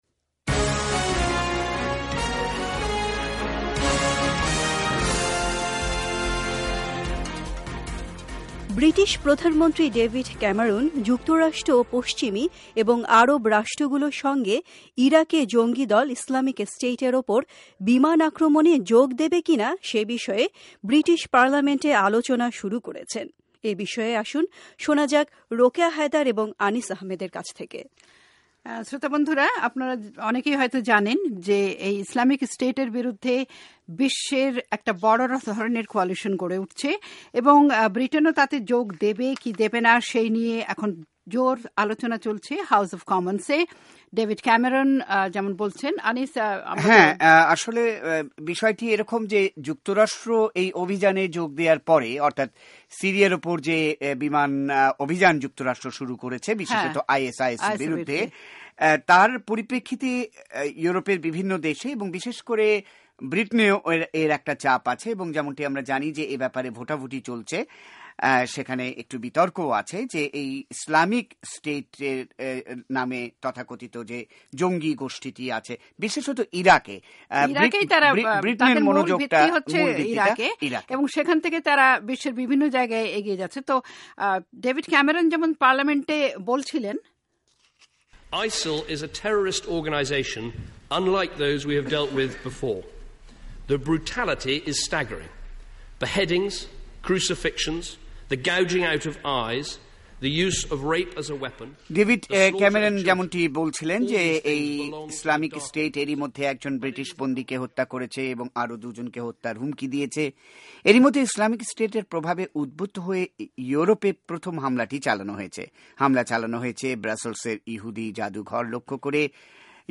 আলোচনা ভিত্তিক রিপোর্টিঃ